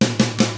146ROCK I1-R.wav